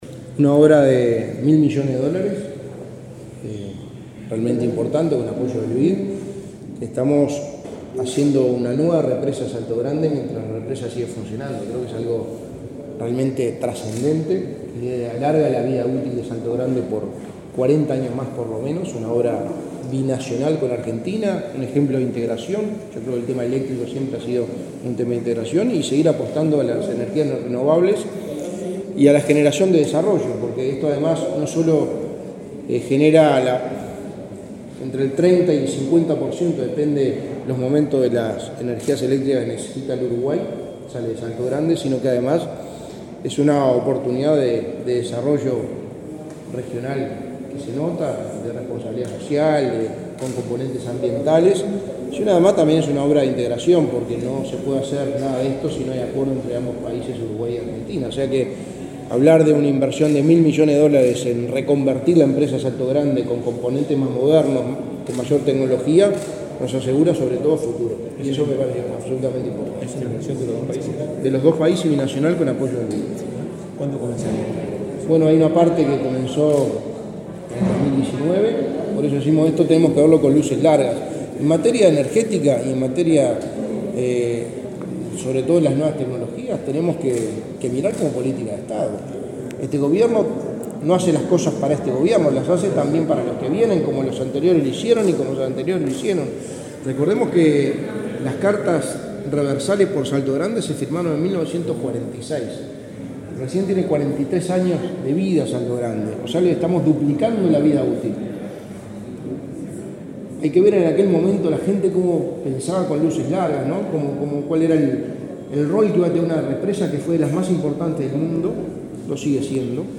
Declaraciones del secretario de Presidencia, Álvaro Delgado
Luego dialogó con la prensa.